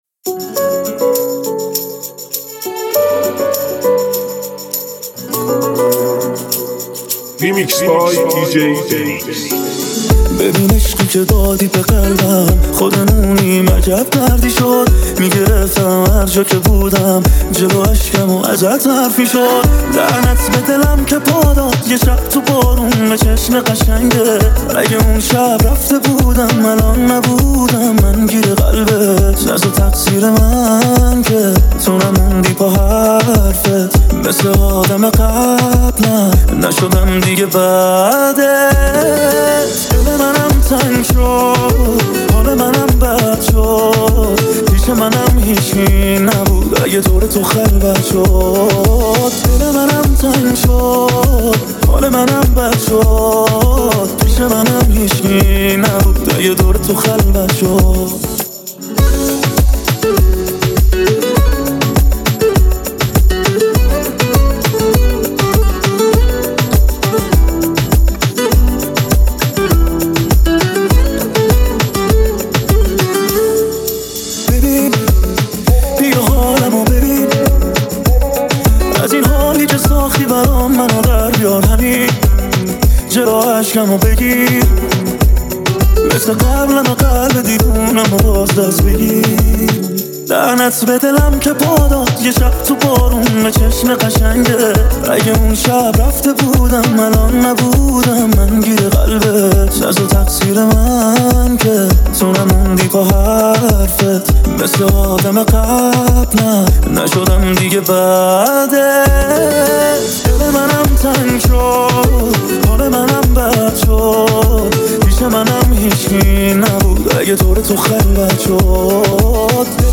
ریمیکس تند بیس دار